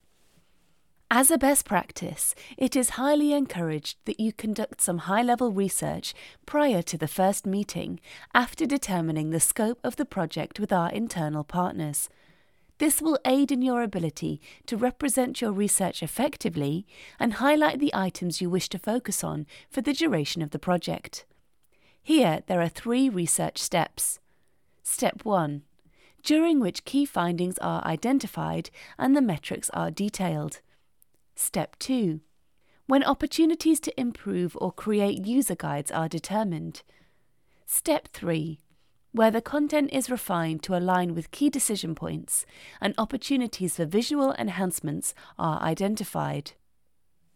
Listen to how it sounds read aloud:
It’s so hard to focus – the meaning gets lost in long words and parenthetical phrases.